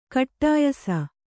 ♪ kaṭṭāyasa